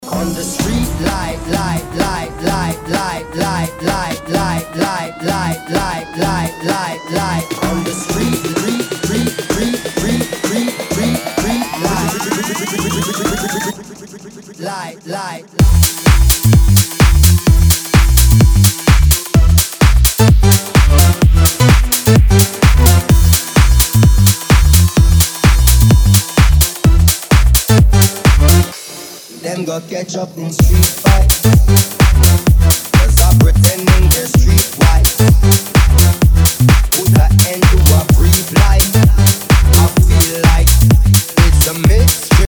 DJ and producer of tech house & house music
His style is unique and electrifying.
DJ